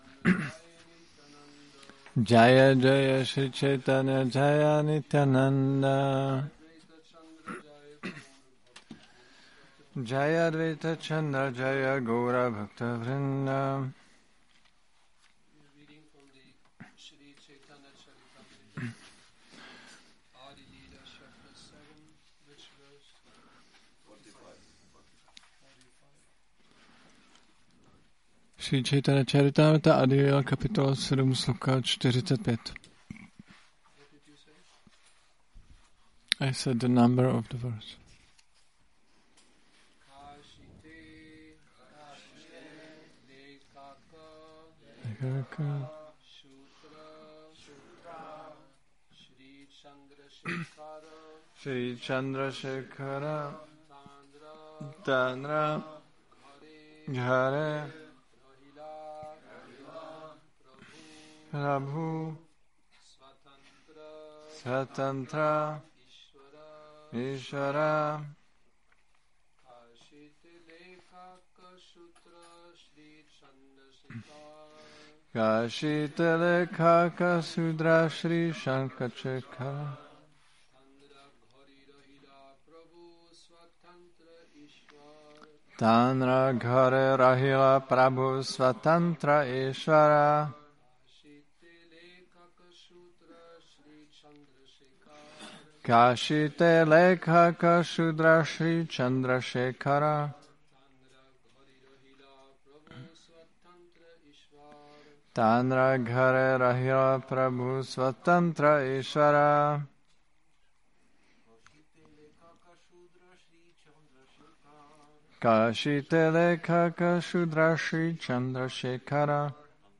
Přednáška CC-ADI-7.45